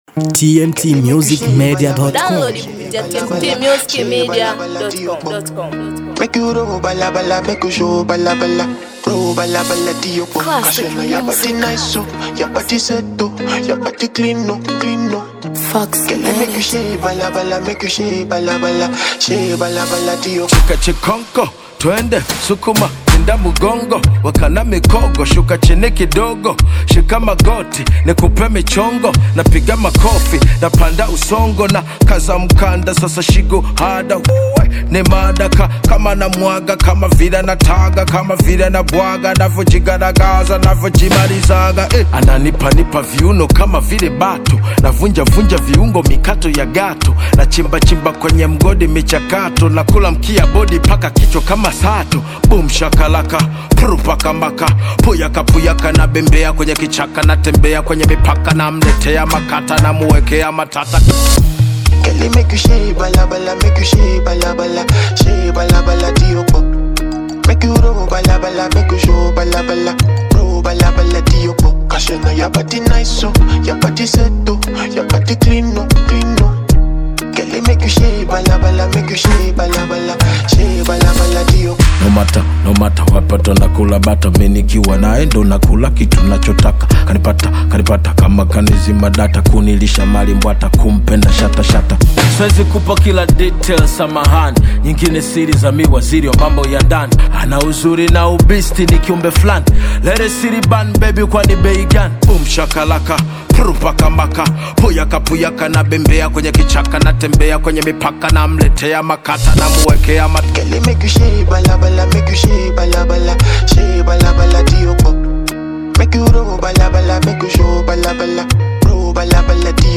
BONGO HIP HOP